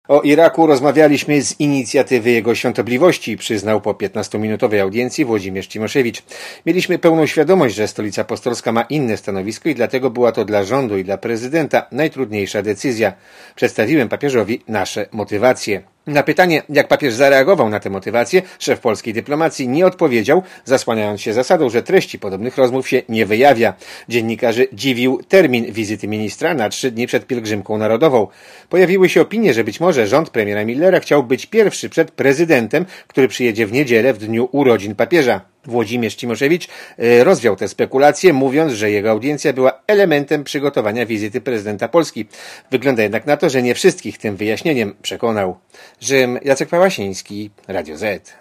Korespondencja z Włoch (380Kb)